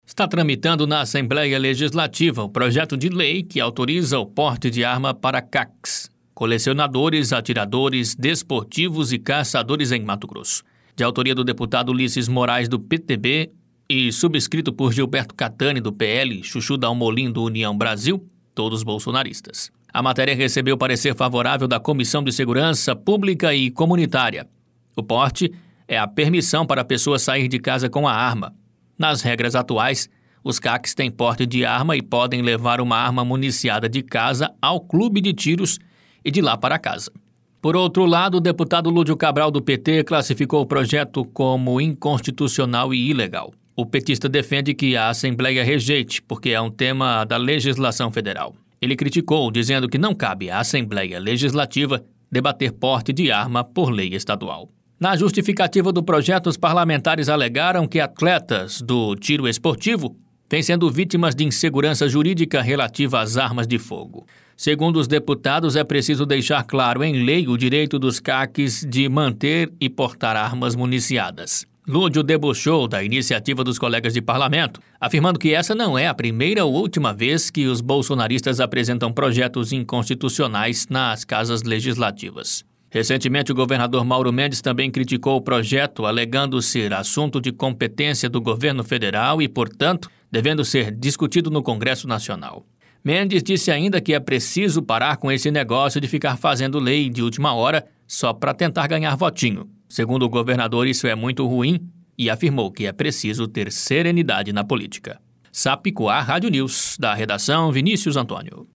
Boletins de MT 18 jun, 2022